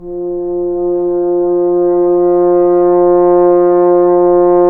Index of /90_sSampleCDs/Roland LCDP12 Solo Brass/BRS_French Horn/BRS_F.Horn 3 pp